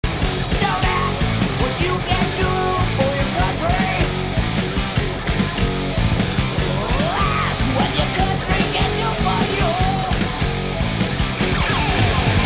This is not a guitar sound, vocal, drum or even a bass!